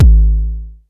Index of /musicradar/essential-drumkit-samples/Vermona DRM1 Kit
Vermona Kick 04.wav